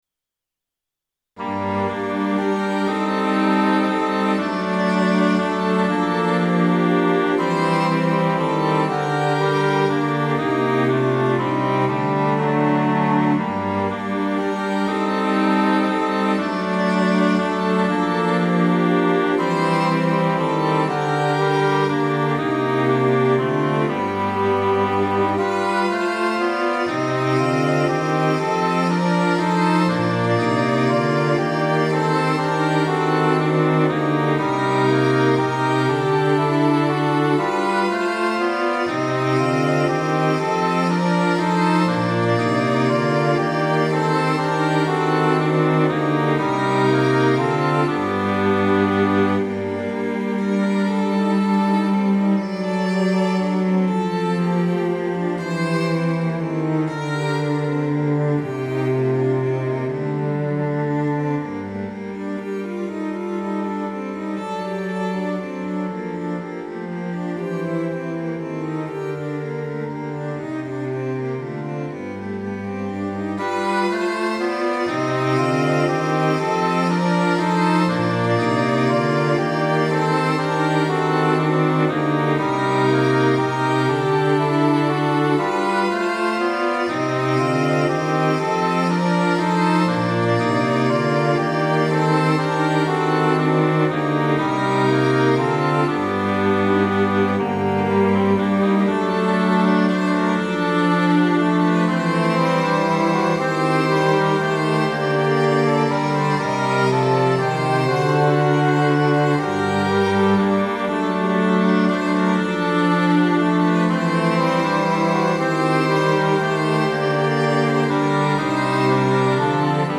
Score PDF Choir　(前奏記載なし) PDF Organ　(前奏記載なし)
▼DL↓   1.0 フルート オーボエ イングリッシュホルン チェロ